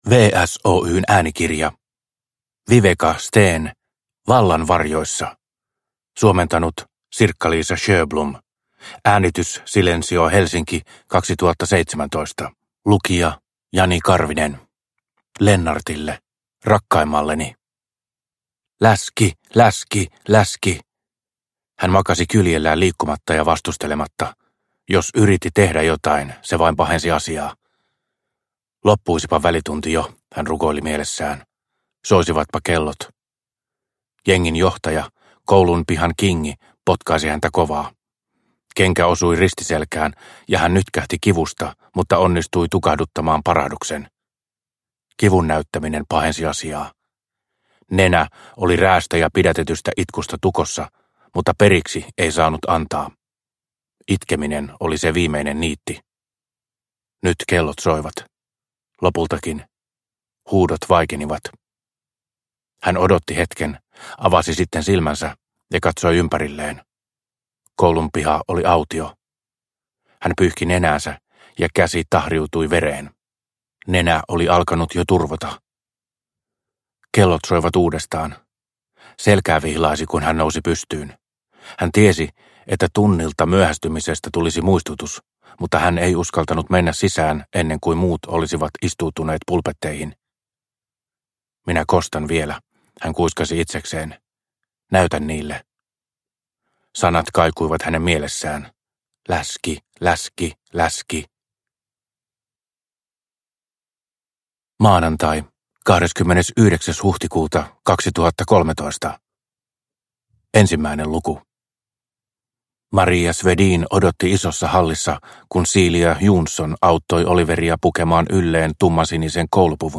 Vallan varjoissa – Ljudbok – Laddas ner